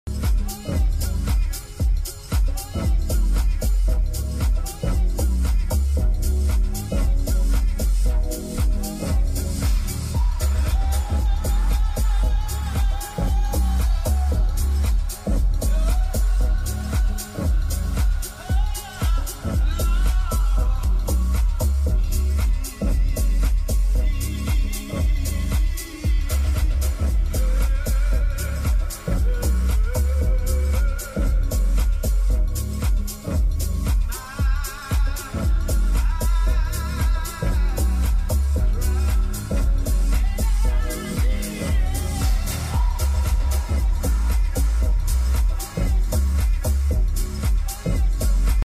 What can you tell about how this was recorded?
Otherwise, this is the song played inside the Mercedes-Benz V300d, Burmester 16-Speaker sound System.